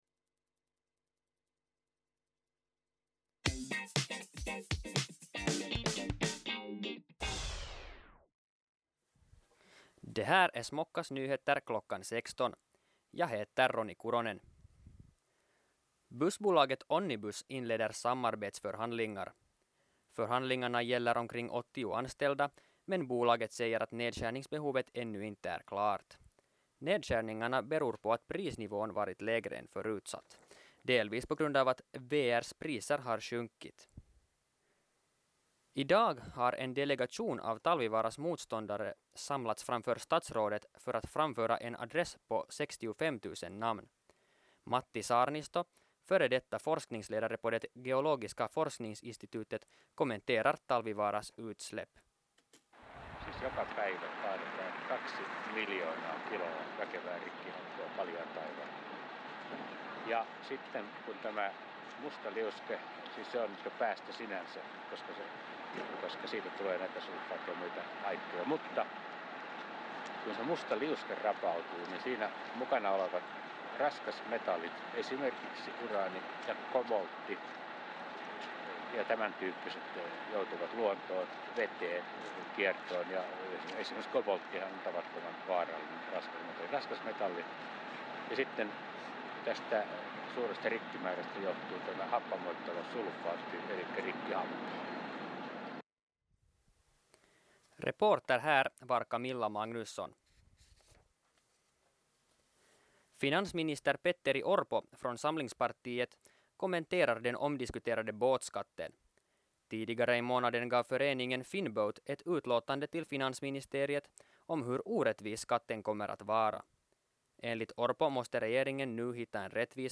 Radionyheter kl. 16